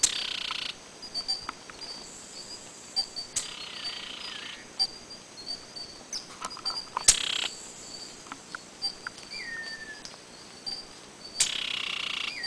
Synallaxis ruficapilla (Vieillot, 1819)
Nome em Inglês: Rufous-capped Spinetail